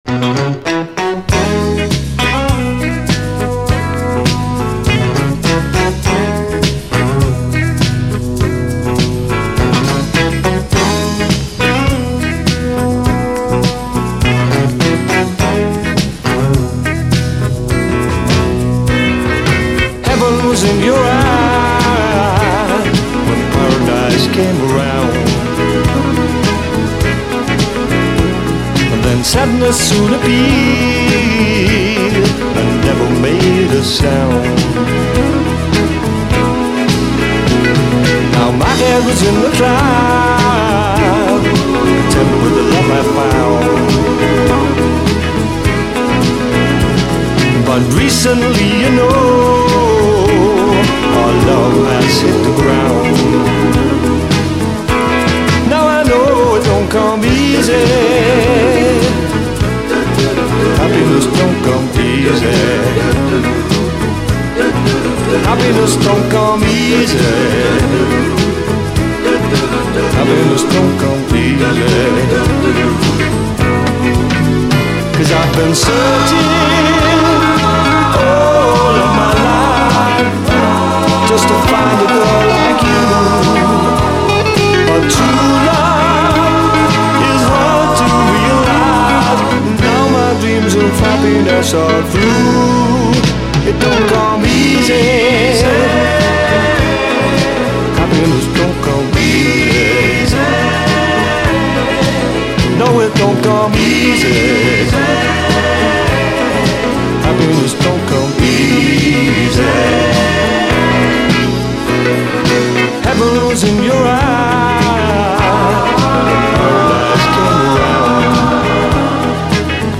SOUL, 70's～ SOUL, SSW / AOR, ROCK, 7INCH
詳細不明、プリAOR的なUK産オブスキュア・メロウ・シンセ・モダン・ソウル45！